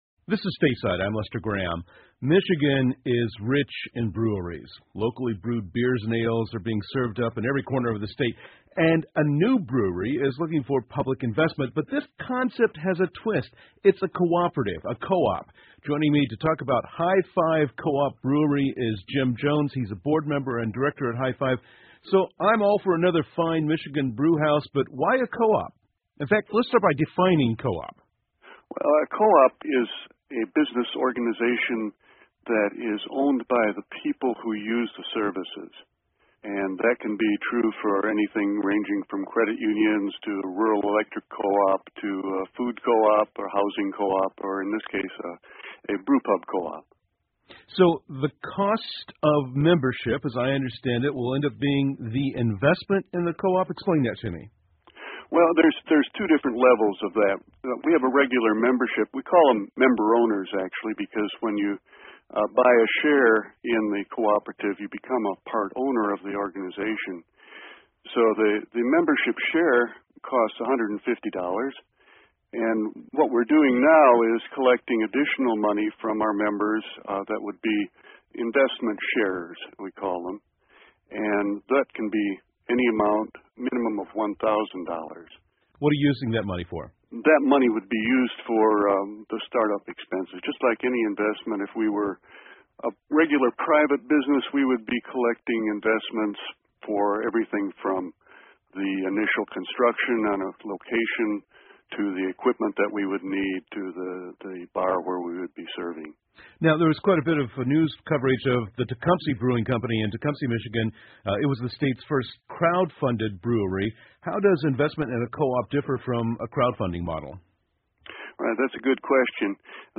密歇根新闻广播 密歇根第一个合作社式啤酒厂落户大急流城 听力文件下载—在线英语听力室